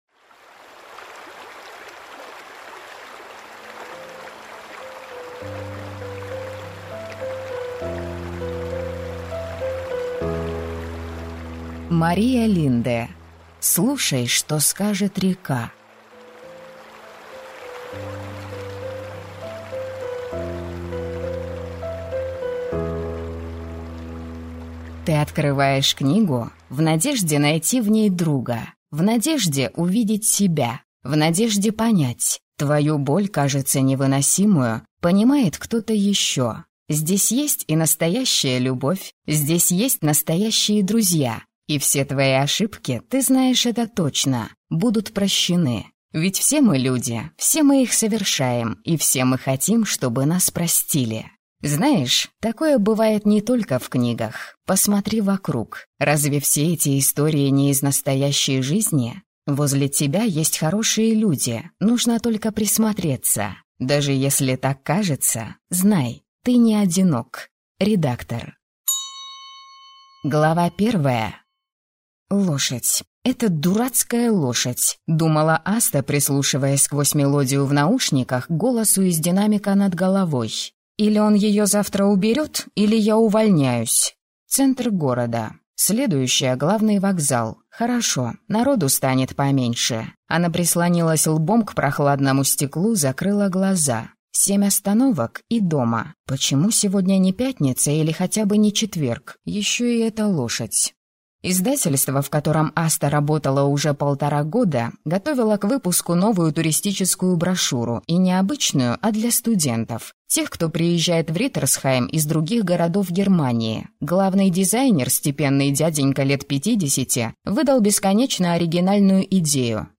Аудиокнига Слушай, что скажет река | Библиотека аудиокниг